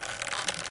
ropes_creak.2.ogg